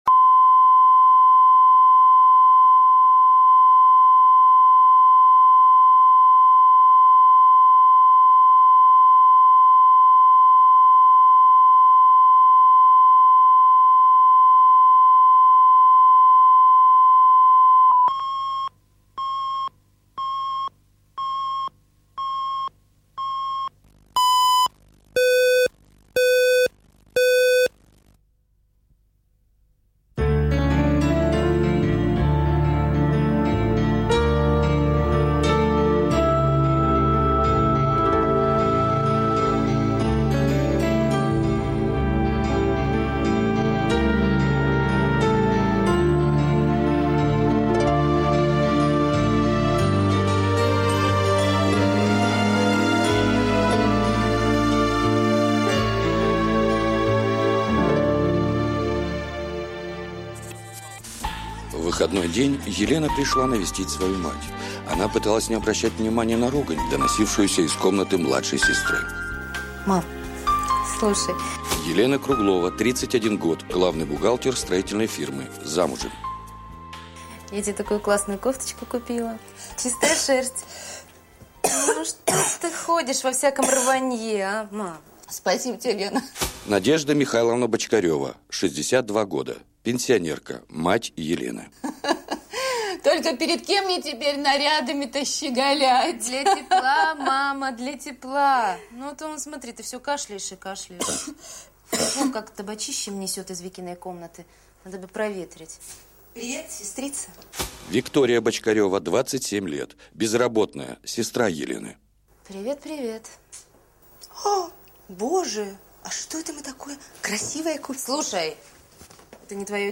Аудиокнига Бедные родственники